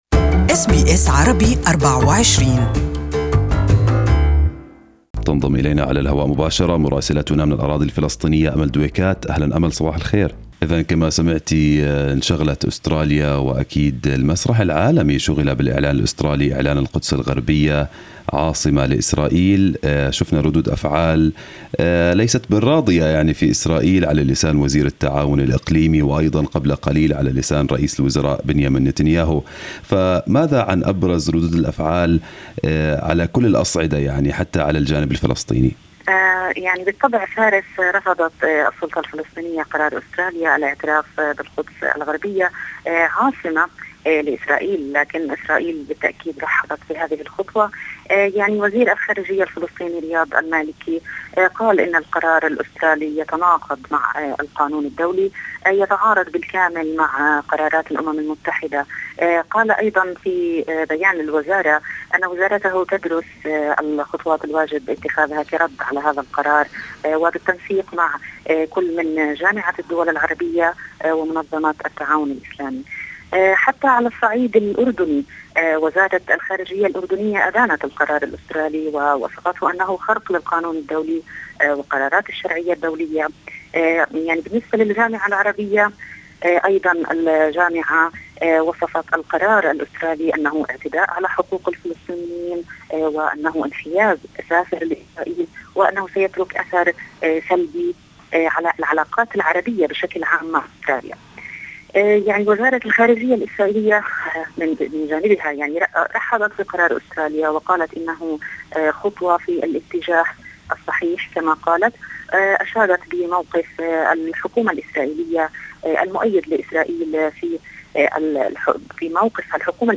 Listen to the full report in Arabic in the audio above.